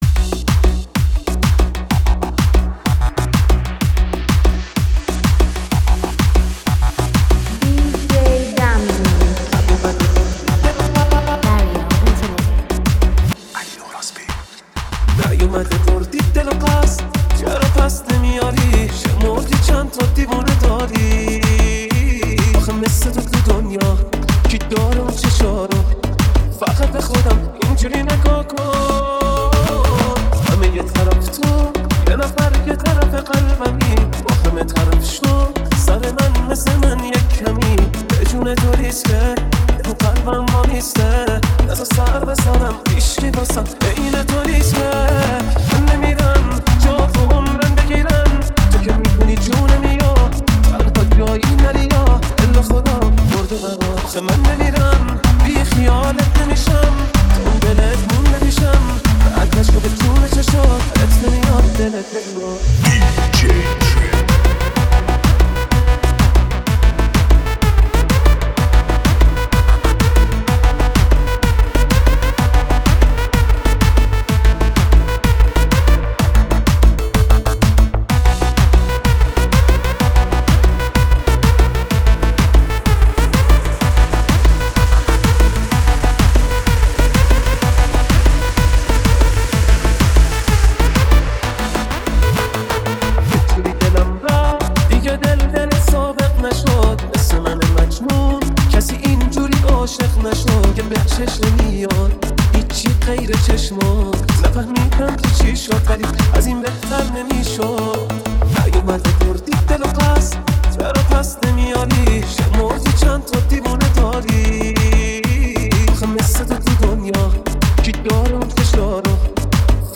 بیس دار